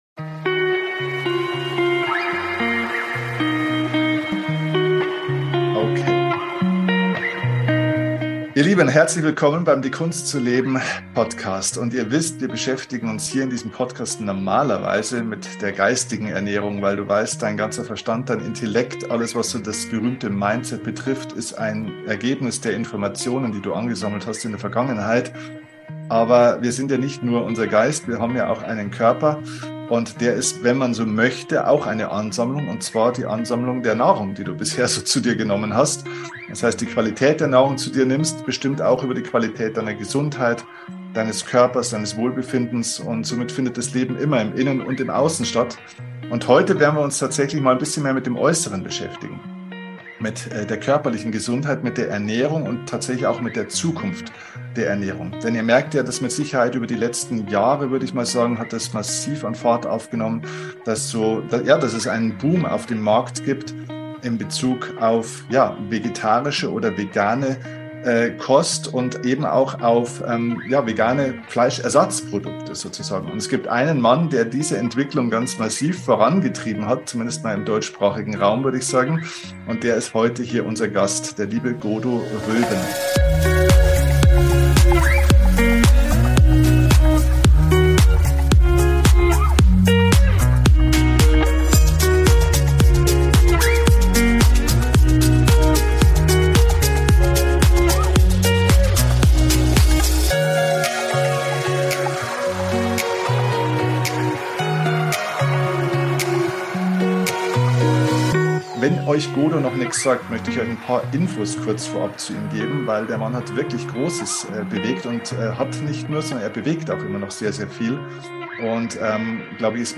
#520 Die Zukunft der Ernährung - Interview